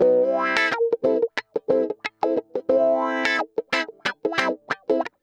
Index of /90_sSampleCDs/USB Soundscan vol.04 - Electric & Acoustic Guitar Loops [AKAI] 1CD/Partition C/05-089GROWAH